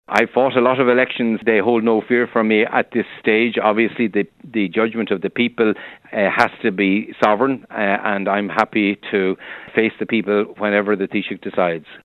Minister for Communications, Richard Bruton, says he’s happy to let people judge Fine Gael on their track record: